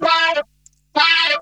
VOC WAADUP.wav